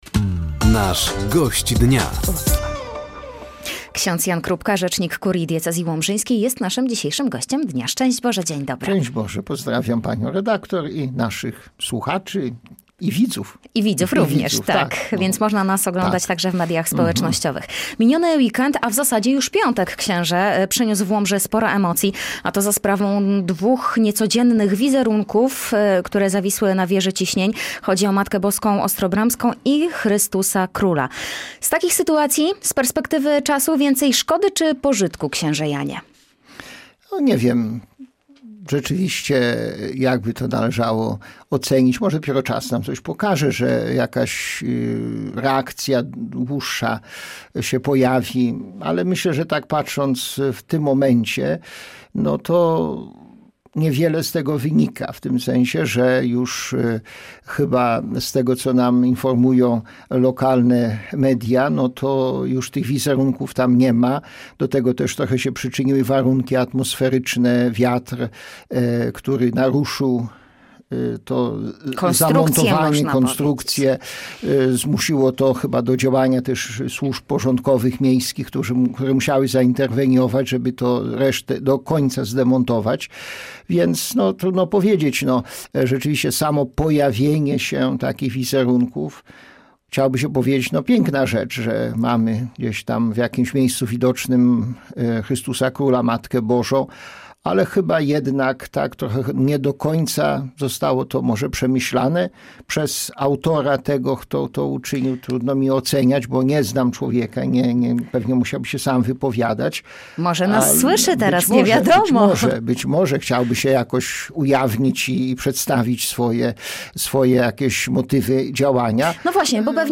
Zachęcamy do wysłuchania i obejrzenia rozmowy: